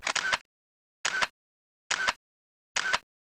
cameraburst.aac